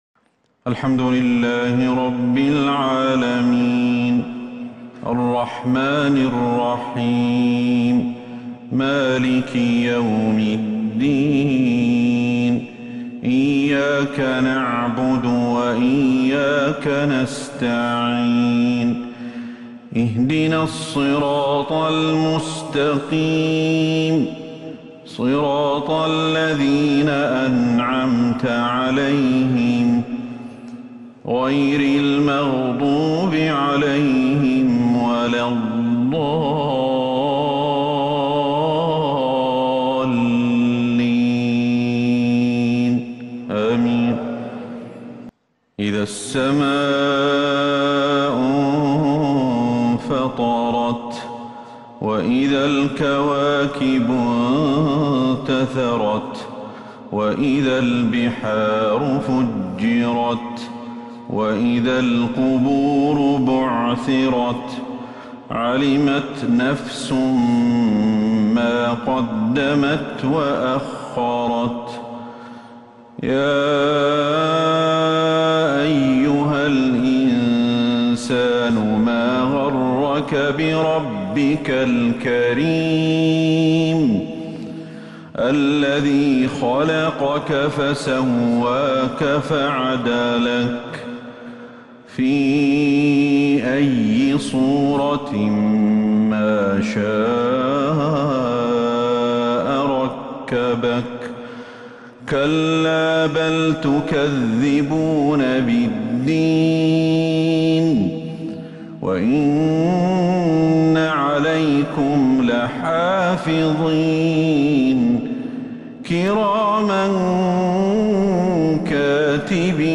مغرب الإثنين 17 ربيع الثاني 1443هـ سورتي {الانفطار}{الطارق} > 1443 هـ > الفروض